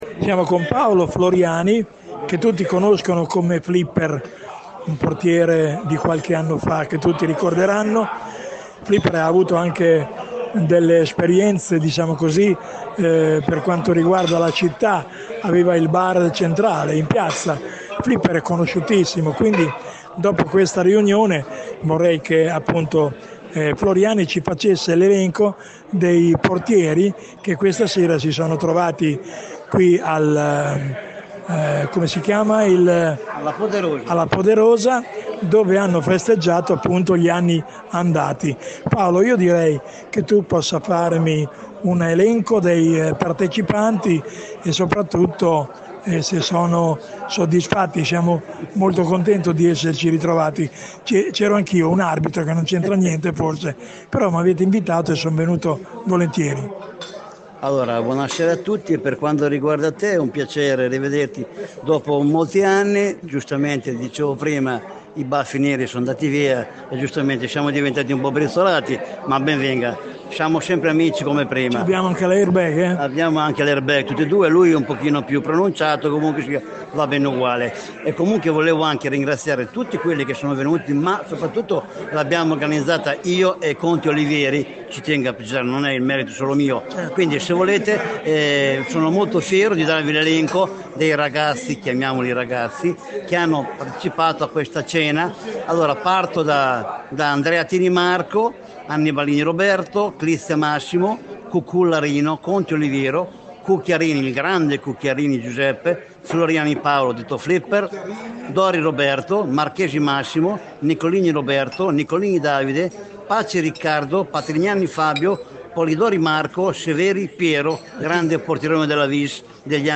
L’incontro è svolto ieri sera al ristorante Casa Poderosa, dove i portieri della gloriosa Victoria Calcio e non solo, si sono ritrovati in un clima di festa per ricordare, nel segno dell’amicizia e della passione per il calcio, le gesta sportive trascorse tra i pali. Ai nostri microfoni uno dei protagonisti: